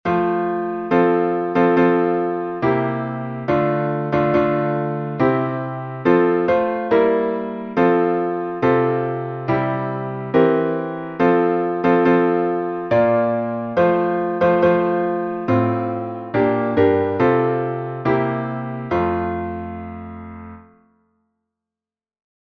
salmo_1B_instrumental.mp3